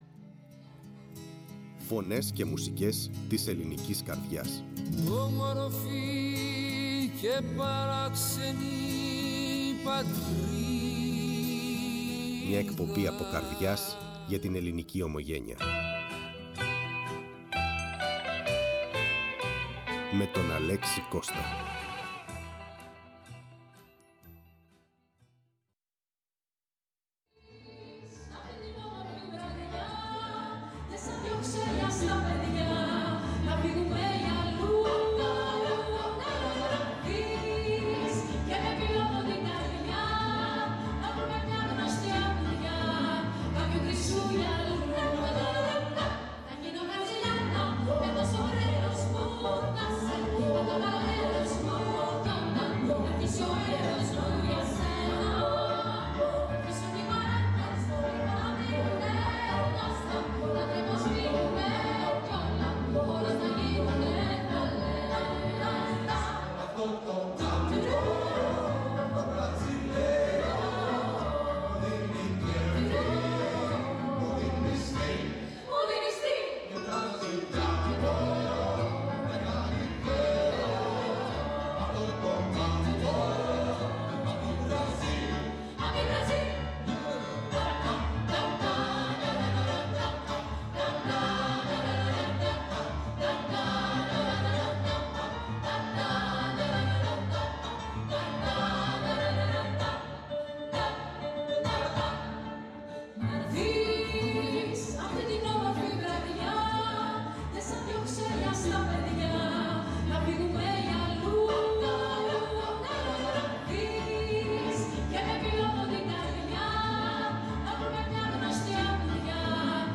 Φλερτάρει με όλα σχεδόν τα είδη μουσικής και τολμά να αλλάζει ηχοχρώματα και ρυθμό σε γνωστά και αγαπημένα τραγούδια που αντέχουν στο χρόνο, με ένα απαιτητικό ρεπερτόριο που “παντρεύει” ακούσματα pop, rock, gospel, jazz, spirituals, παραδοσιακά κ.α.
Συνεντεύξεις